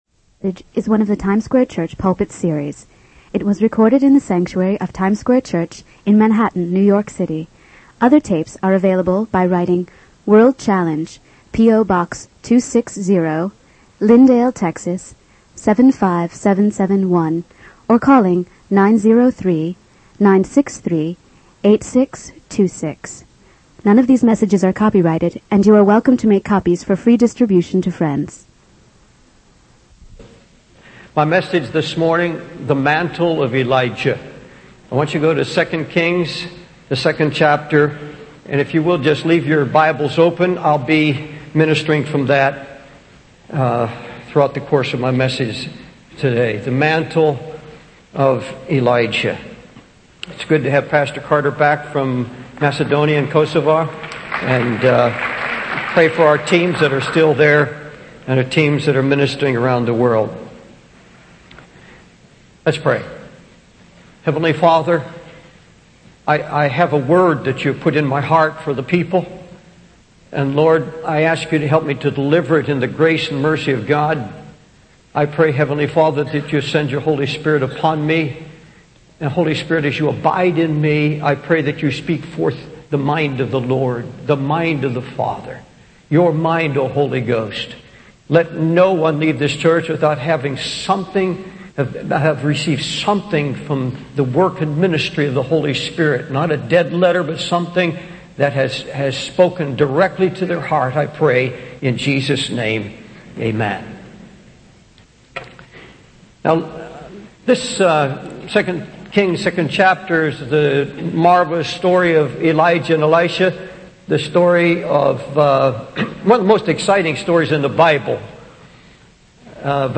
It was recorded in the sanctuary of Times Square Church in Manhattan, New York City.